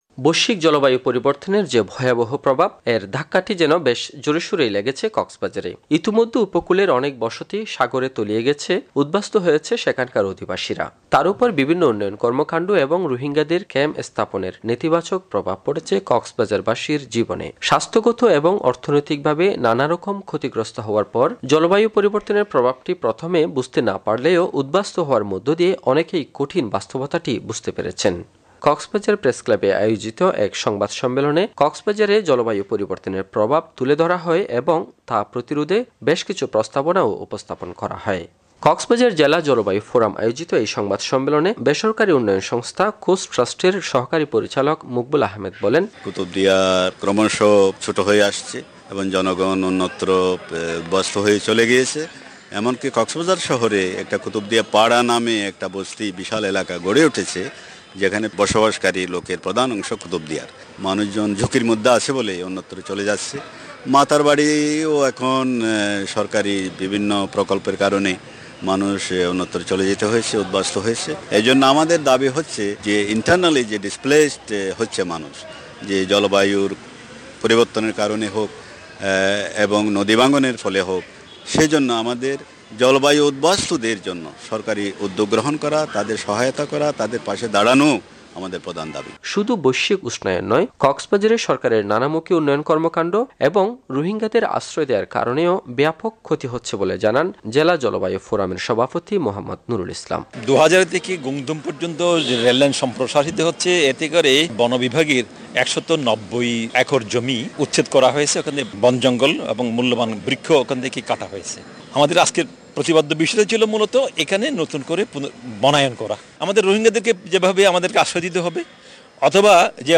কক্সবাজার থেকে
রিপোর্ট